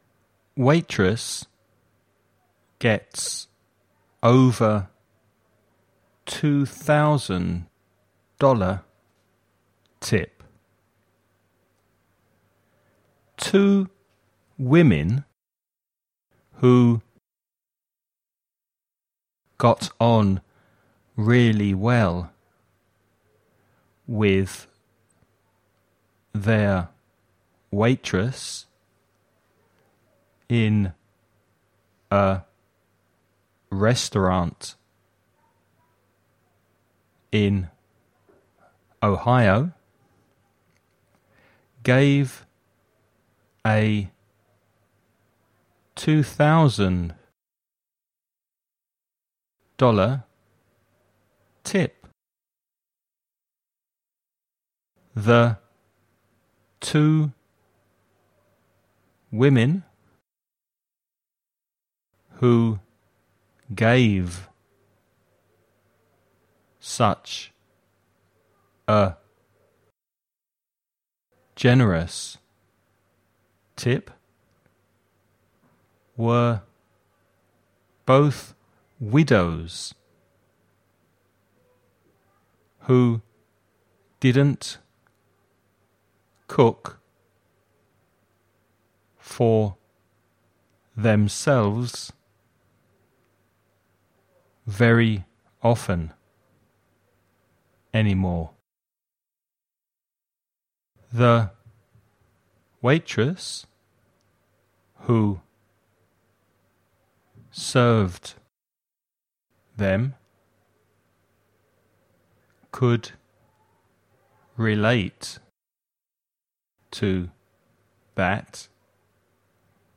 Dictation
(Primero escucha el dictado a una velocidad normal)